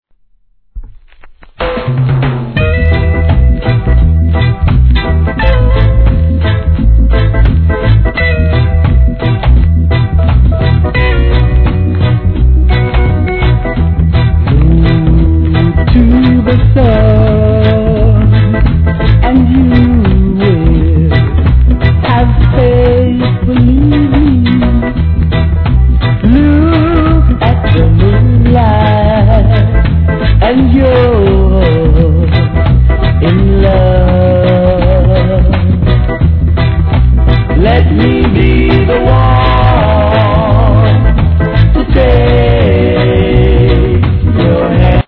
盤面に薄いキズありますが音にはさほど影響ありません
REGGAE